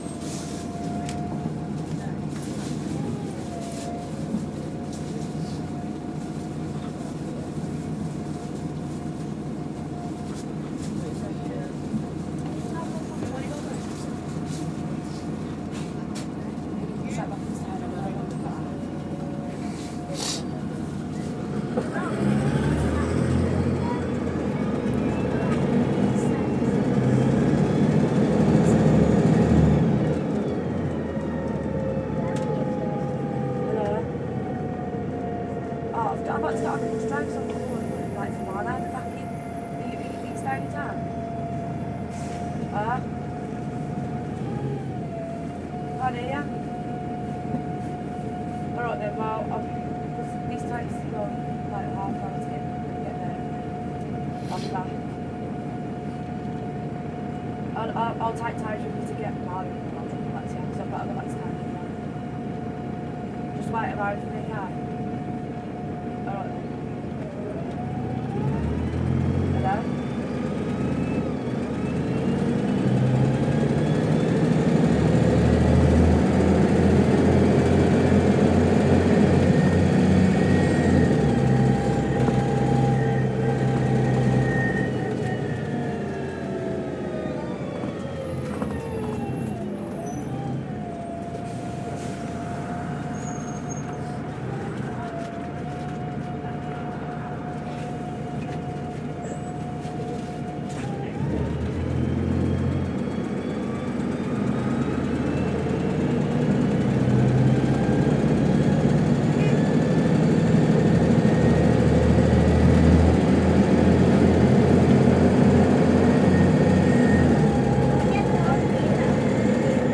Ambience: Bus journey and passengers
Sounds of a moving bus travelling through Birmingham city centre, with vehicle and engine sounds as well as passenger audio. 28.03.14.